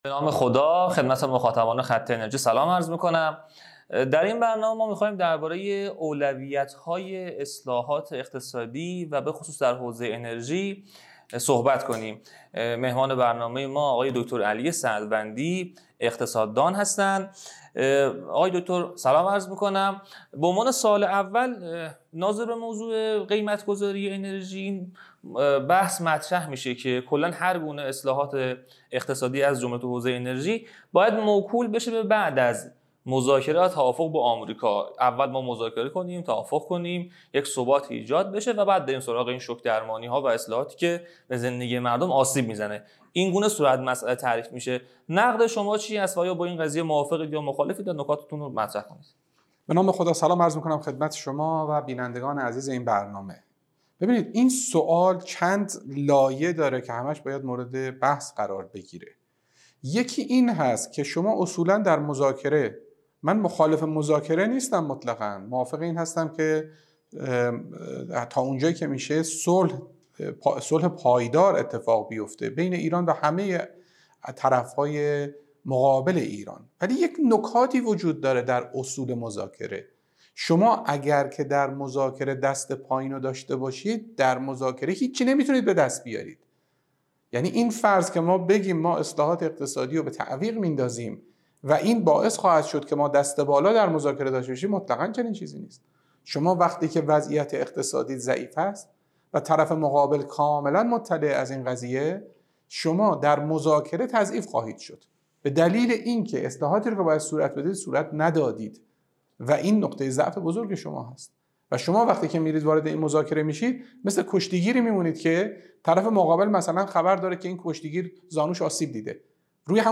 کارشناس اقتصادی توضیح می‌دهد.